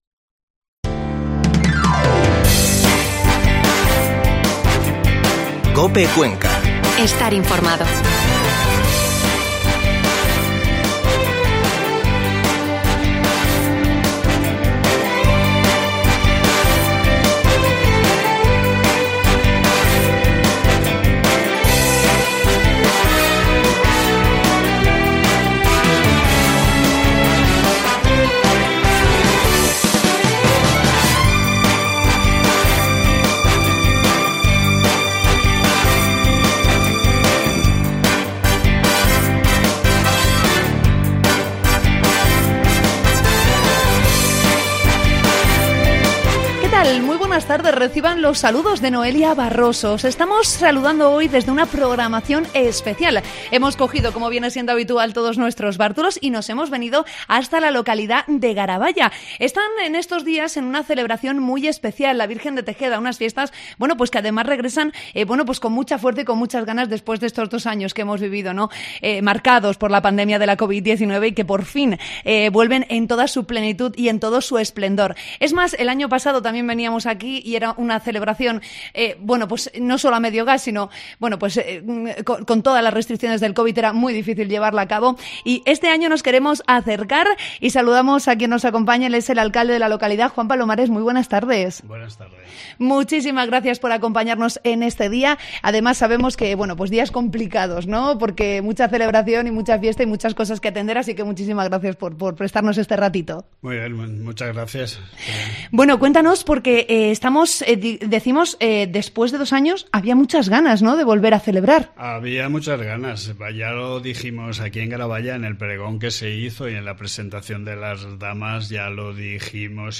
Mediodía en COPE Cuenca desde Garaballa